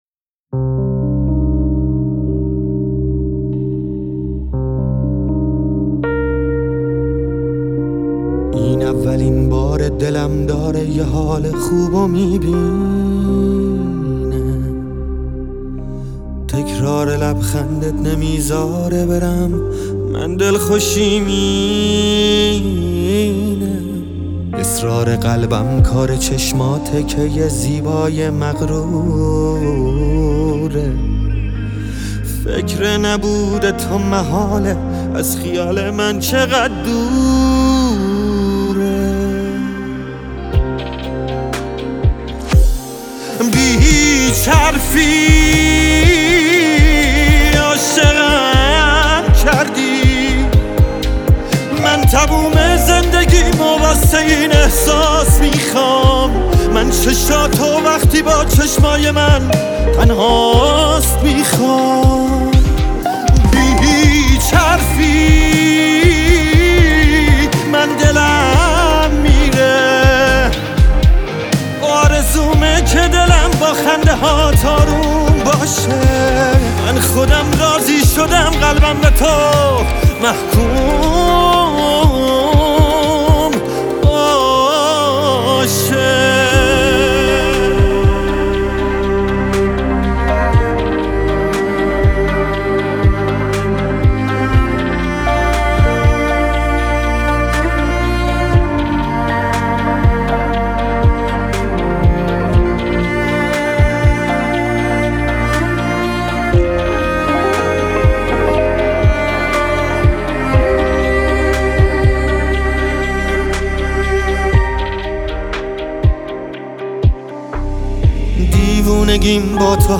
Persian Music